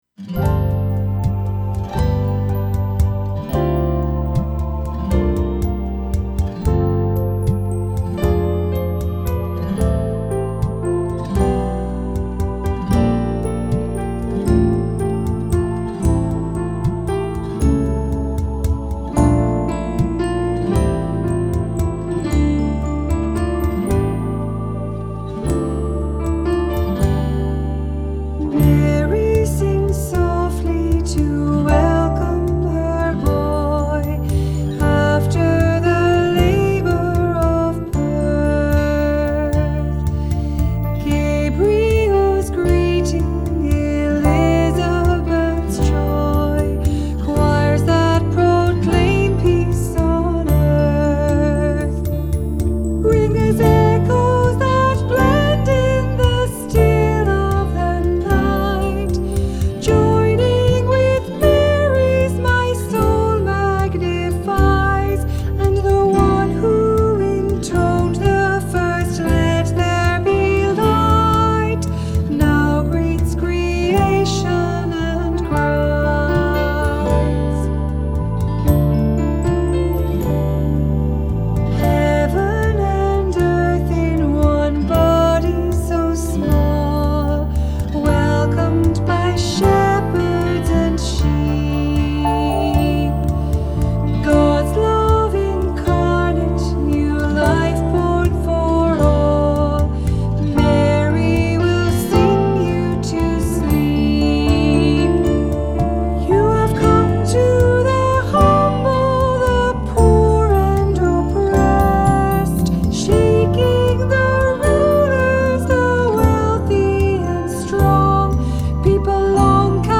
Voicing: "Two-part equal","Cantor","Assembly"